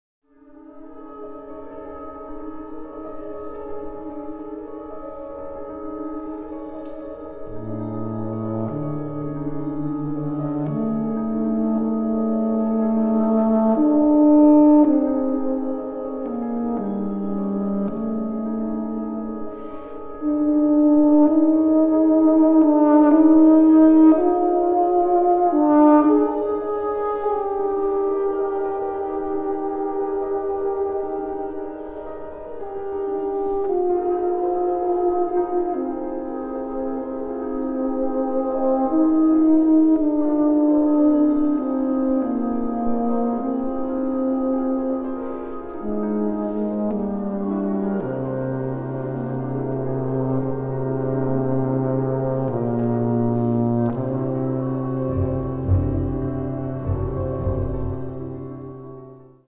Unterkategorie Zeitgenössische Bläsermusik (1945-heute)
Besetzung Ha (Blasorchester)